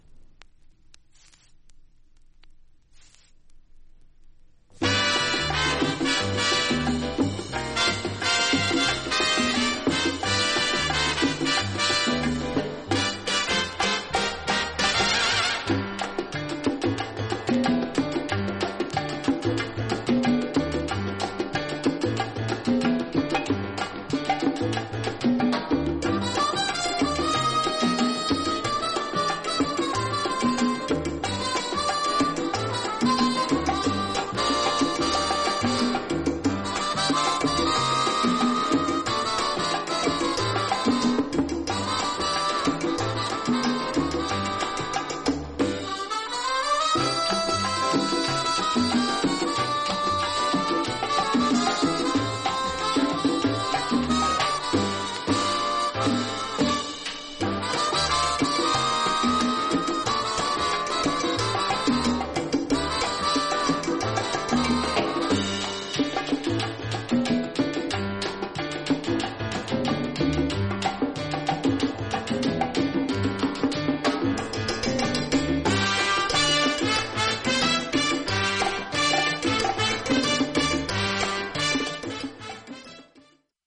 実際のレコードからのサンプル↓ 試聴はこちら： サンプル≪mp3≫